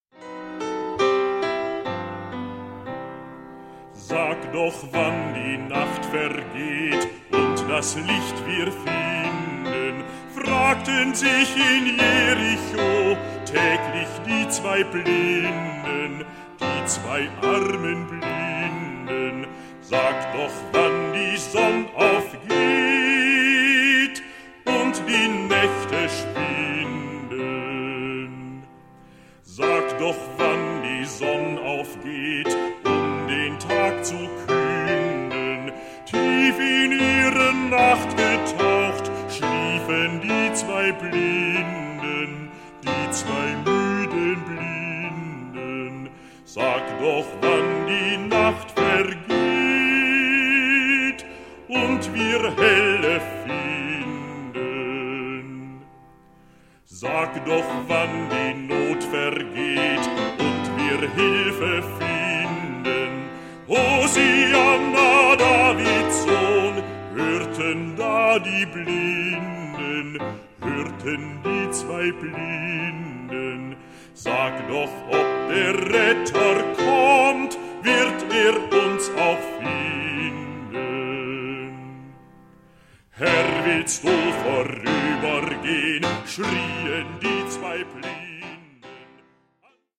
Diese Schallplattenaufnahme stammt aus dem Jahr 1979.